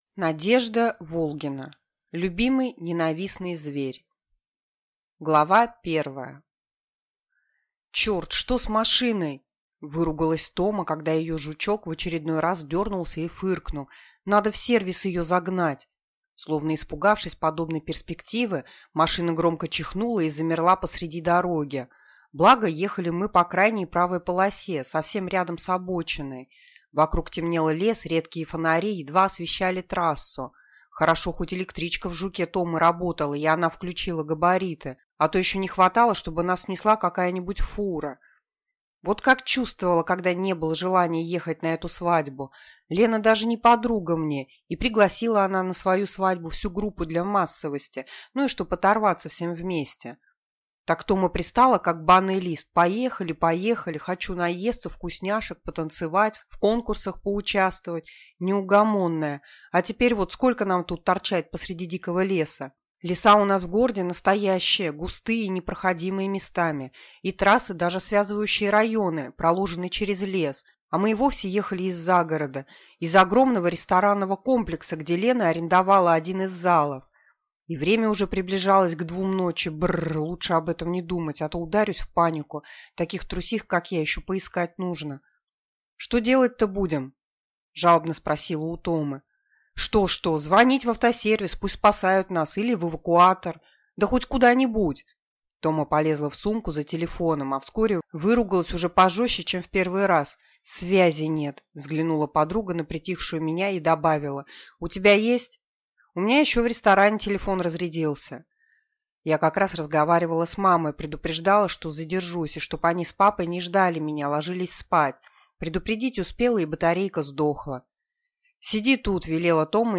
Аудиокнига Любимый ненавистный зверь | Библиотека аудиокниг